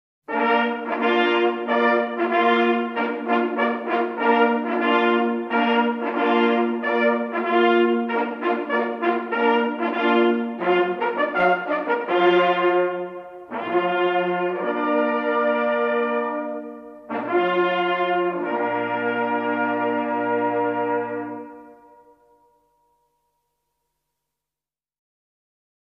Jagdhornbläser
Wenn auch die Jagdhörner in der Zahl der zur Verfügung stehenden Töne begrenzt sind, so vermitteln sie doch mit ihren einfachen Melodienfolgen beim Zuhörer einen unvergleichlichen naturverbundenen Eindruck.